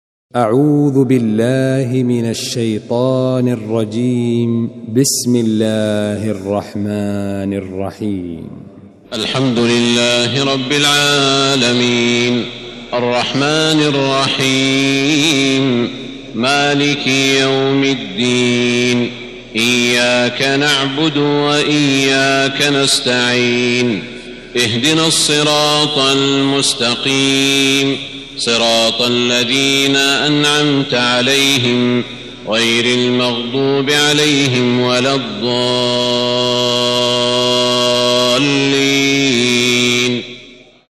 المكان: المسجد الحرام الشيخ: سعود الشريم سعود الشريم الفاتحة